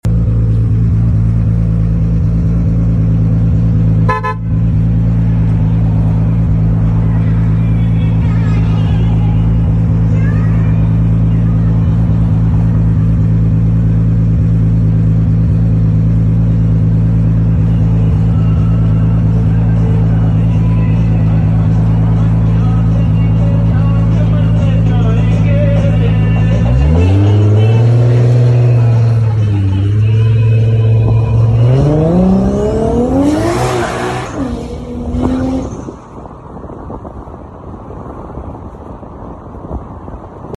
MK4 Supra